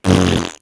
fart1.wav